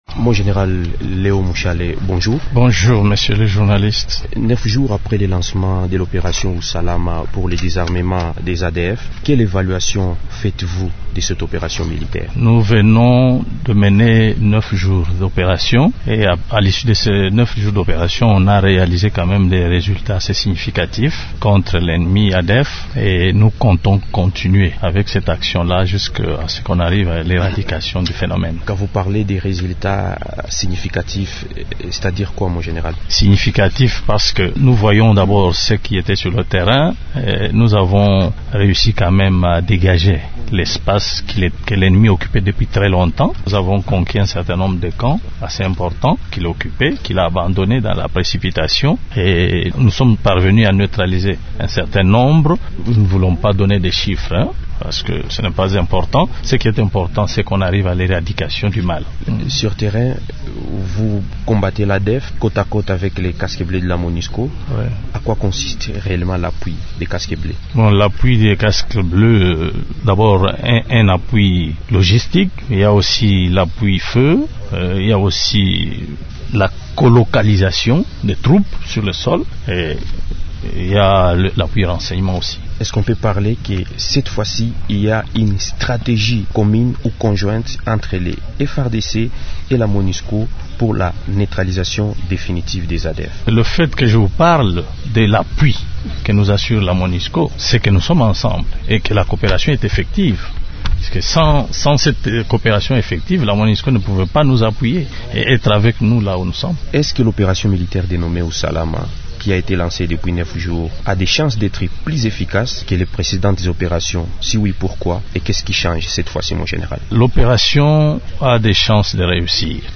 invite_du_jour_leon_mushale_.mp3